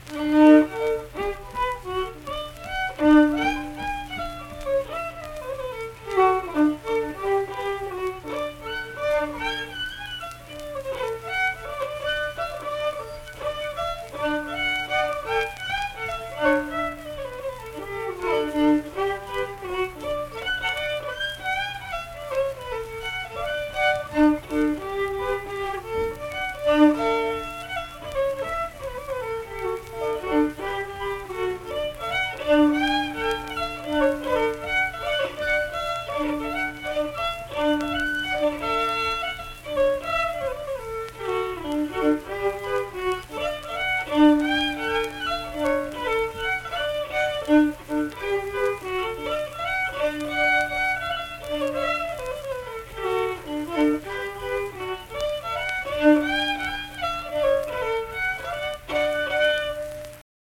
Unaccompanied fiddle performance
Instrumental Music
Fiddle
Middlebourne (W. Va.), Tyler County (W. Va.)